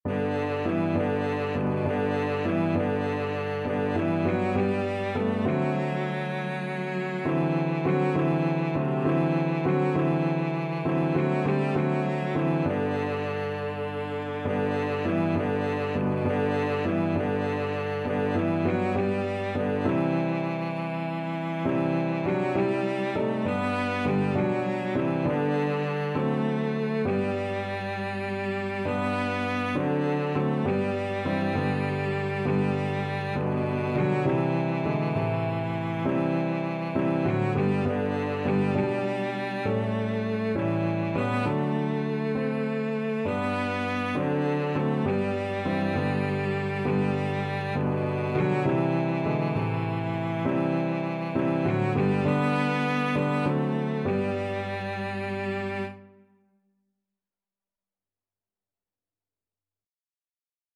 6/8 (View more 6/8 Music)
Classical (View more Classical Cello Music)